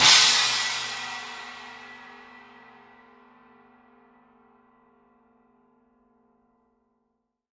Paiste 18" Signature Thin China Cymbal
The Paiste 18″ Signature Series Thin China Cymbal is fairly trashy with bright attack and low wash. Wide frequency range, fairly clean mix. Explosive response, fast fade.
• Volume: Soft to Medium Loud
• Stick Sound: Washy
• Intensity: Lively
• Sustain: Medium Short
18_thin_china_edge.mp3